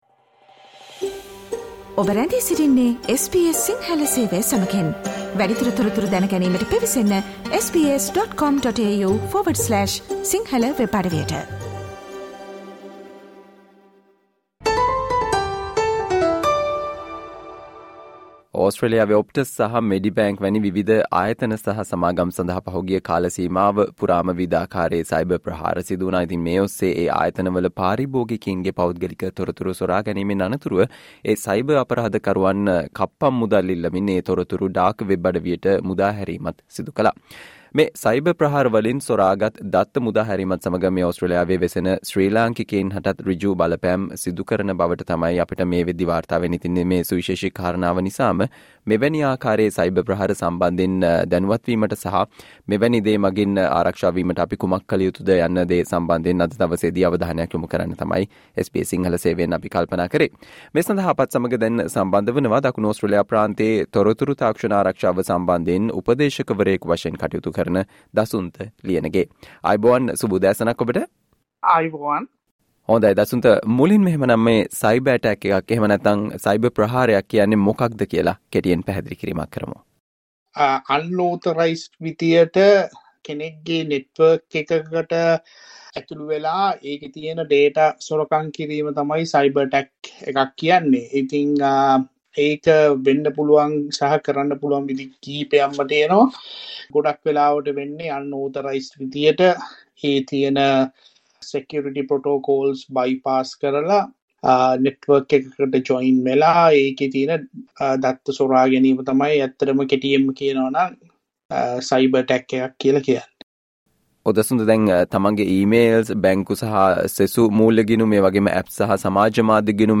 SBS Sinhala discussion on What you should do about suspicious e-mails and text messages you receive